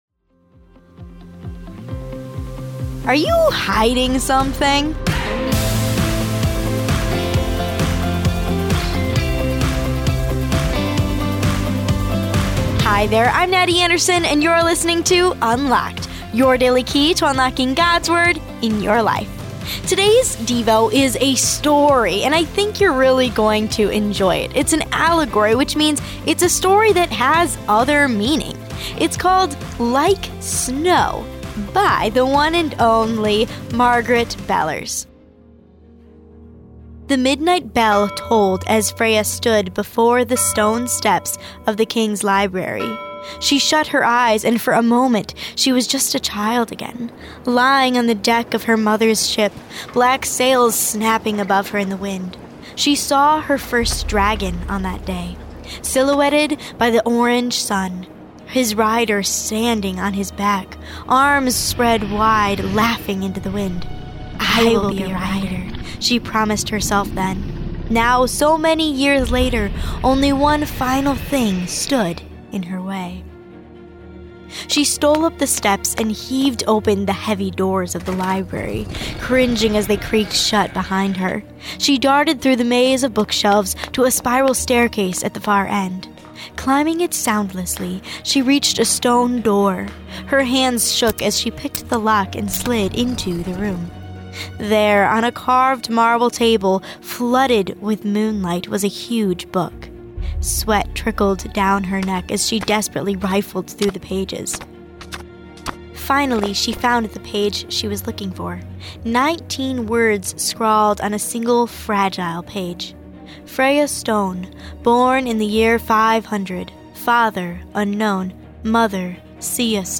Unlocked is a daily teen devotional, centered on God’s Word. Each day’s devotion—whether fiction, poetry, or essay—asks the question: How does Jesus and what He did affect today’s topic?